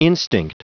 Prononciation du mot instinct en anglais (fichier audio)
Prononciation du mot : instinct